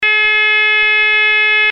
ZUMBADOR - SONIDO CONTINUO
Zumbador electromagnético de fijación mural
Sonido continuo
92dB